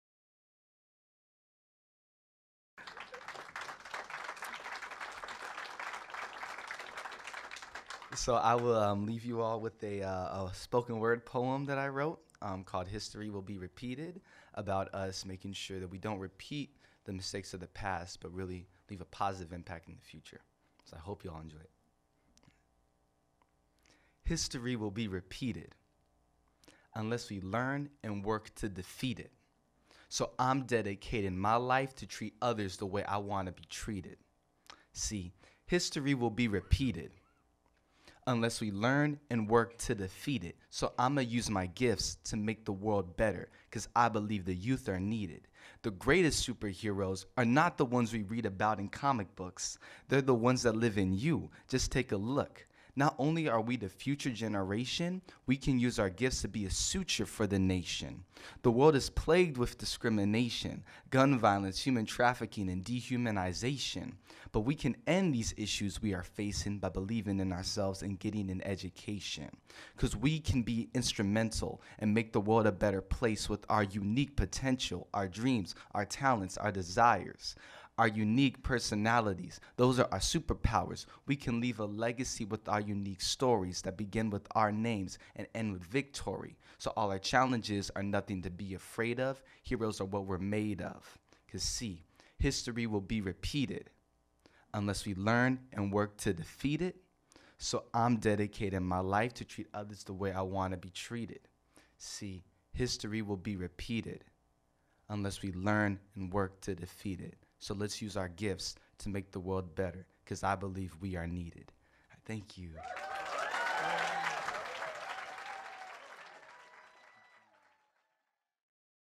Poets